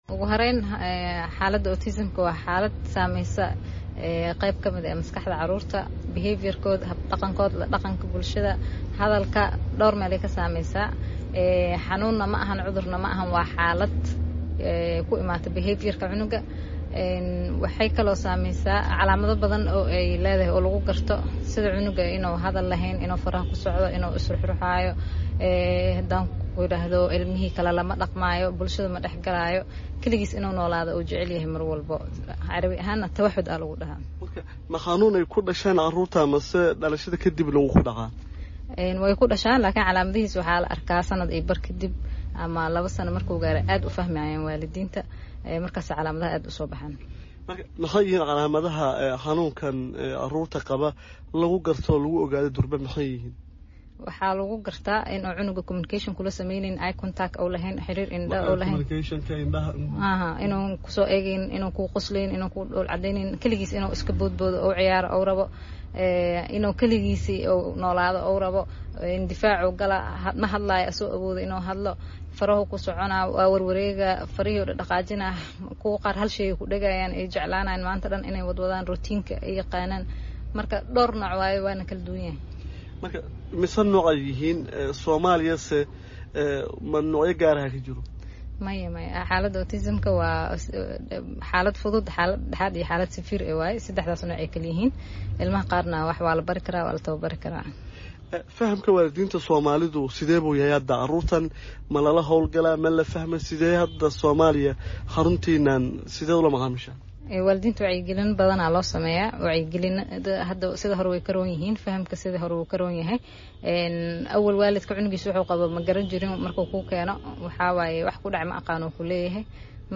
Wareysi: Xaaladda Autism-ka ee Soomaaliya